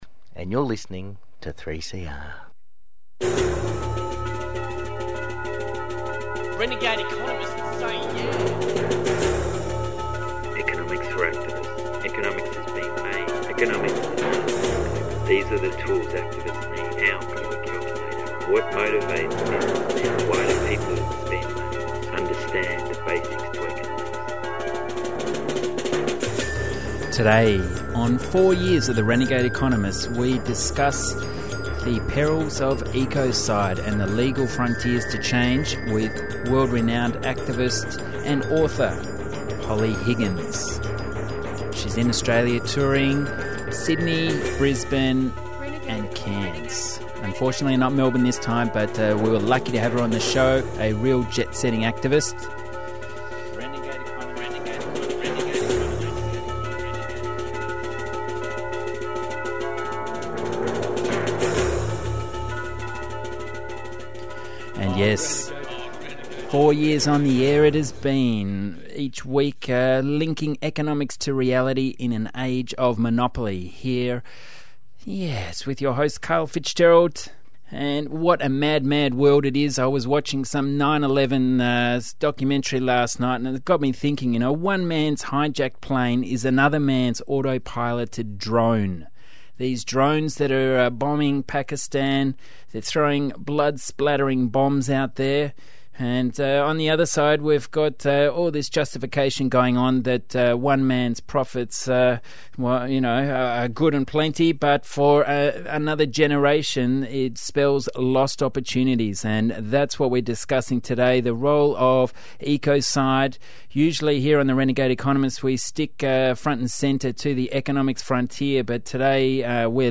Global activist Polly Higgins discusses the legal moves to protect the environment from corporate entities.